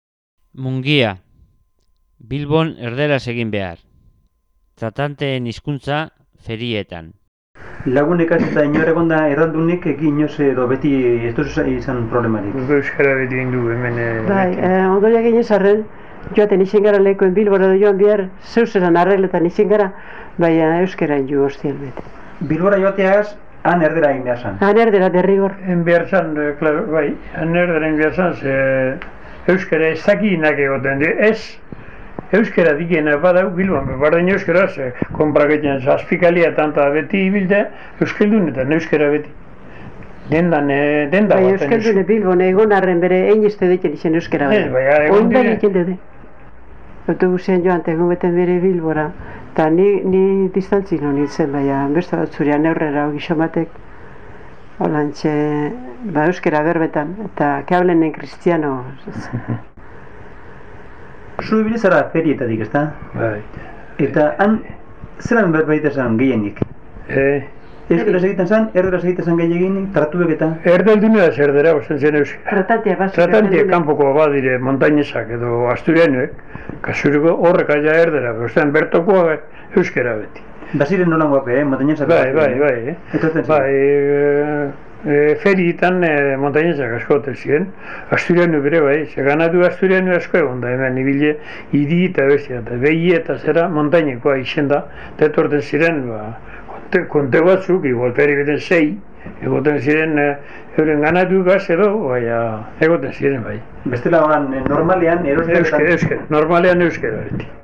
1.10. MUNGIA